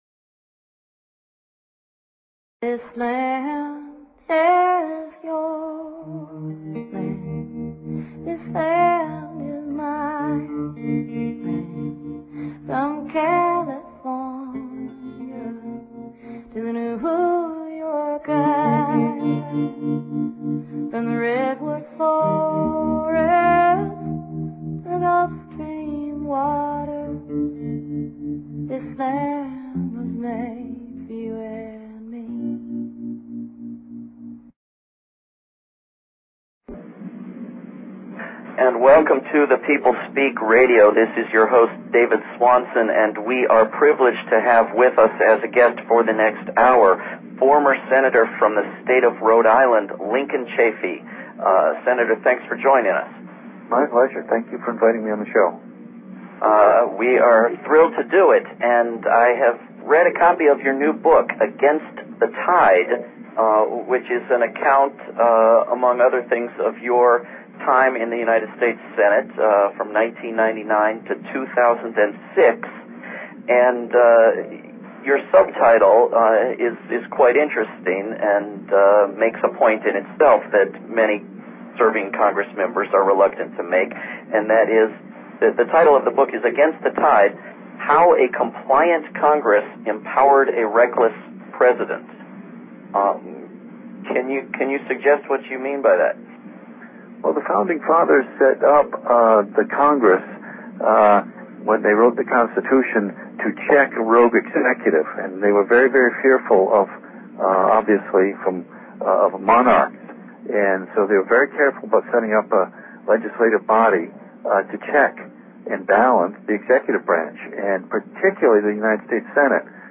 Guest, Lincoln Chafee